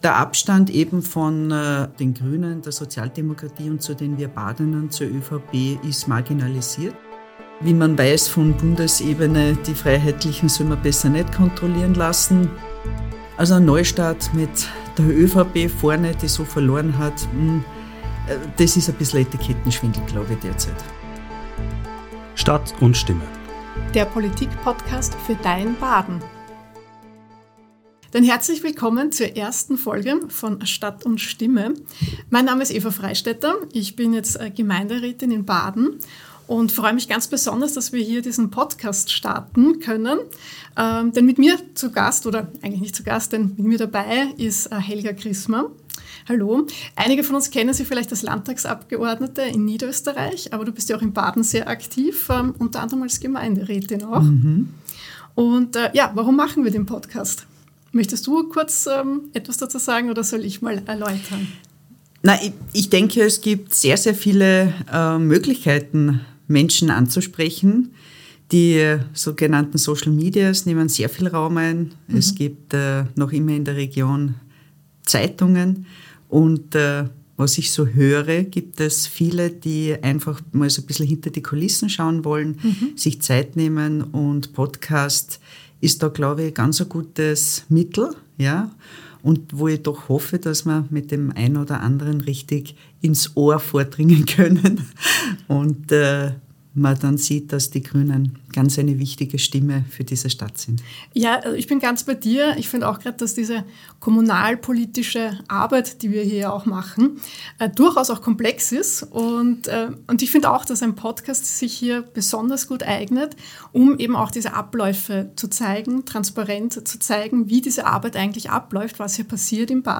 Beschreibung vor 1 Jahr Die Gemeinderatswahl ist knapp zwei Monate her und hat größere Veränderungen mit sich gebracht als viele Beobachter:innen gedacht hätten. Gemeinderätin Eva Freistetter analysiert mit Klubobfrau Helga Krismer die neue politische Lage Badens, sie erläutern, wie sich eine Stadtregierung eigentlich konstituiert und besprechen den ersten kleinen Skandal der neuen ÖVP-SPÖ-Bürgerlisten-Regierung.